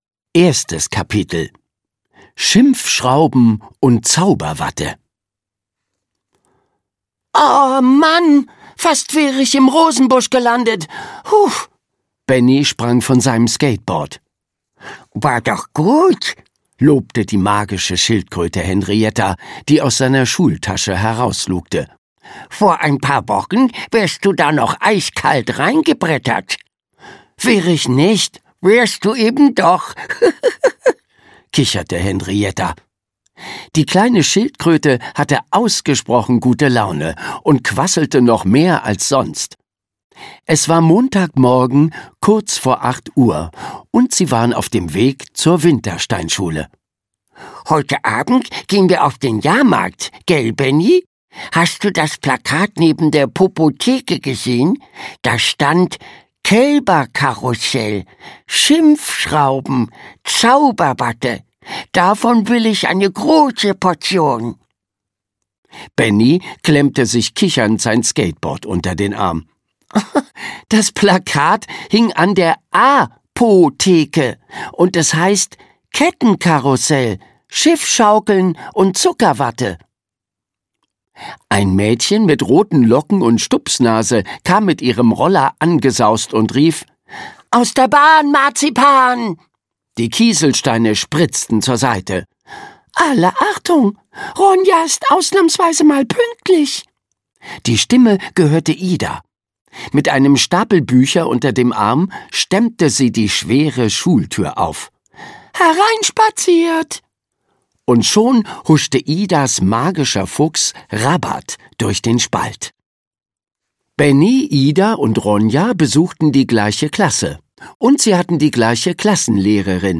- Margit Auer - Hörbuch